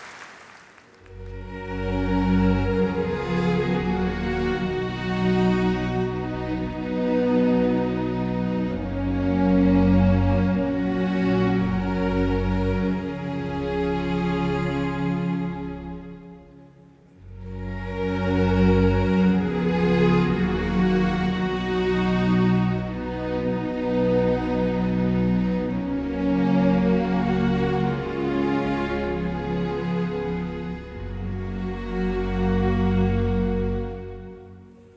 Classical Crossover Classical
Жанр: Классика